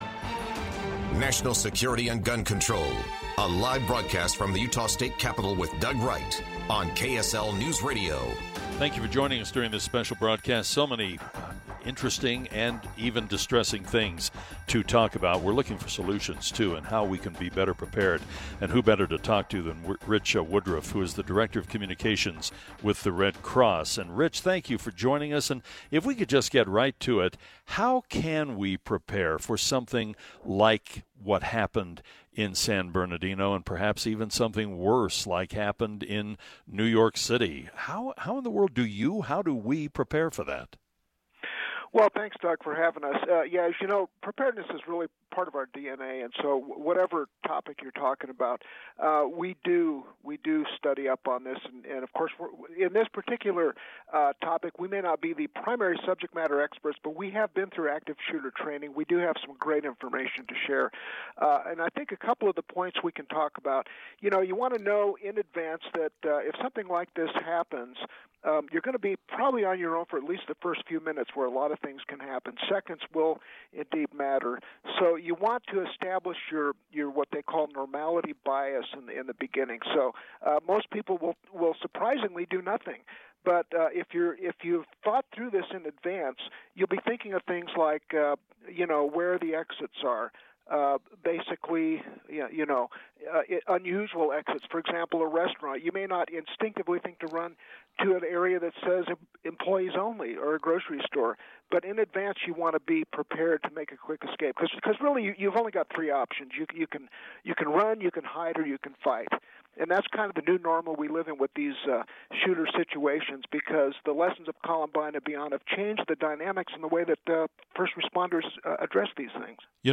Town Hall on National Security and Gun Control